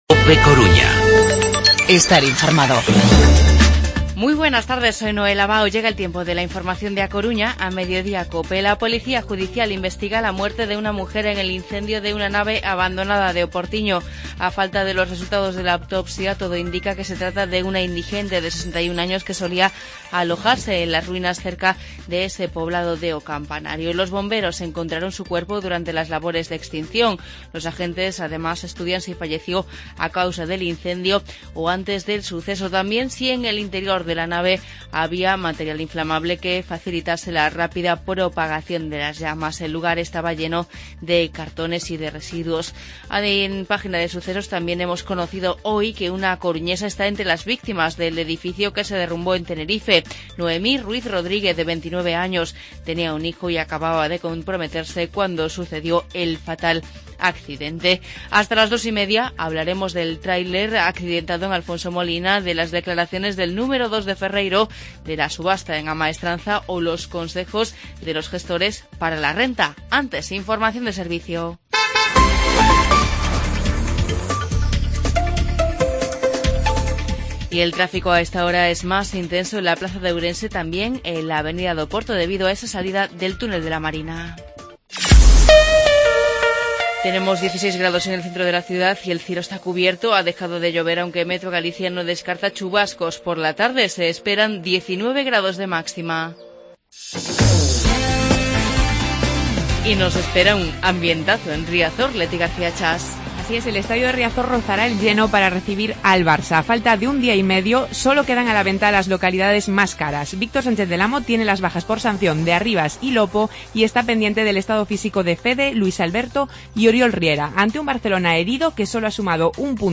Informativo Mediodía COPE Coruña martes, 19 de abril de 2016